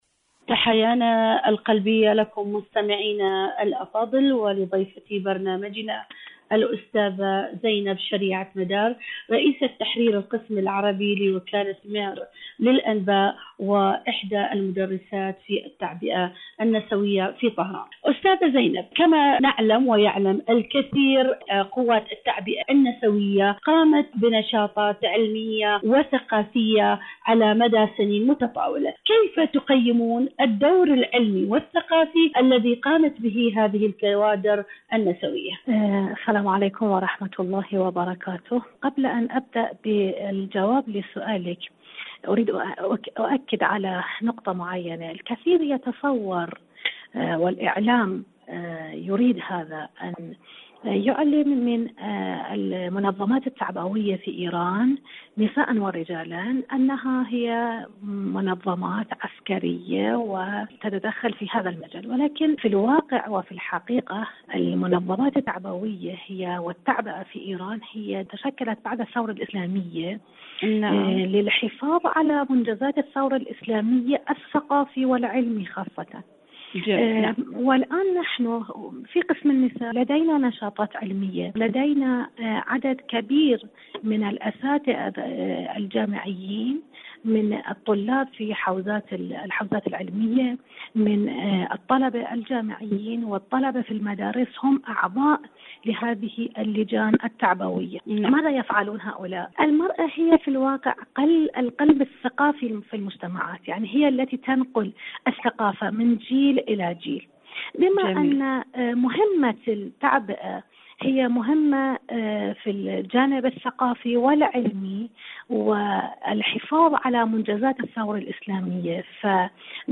إذاعة طهران-ألوان ثقافية: مقابلة إذاعية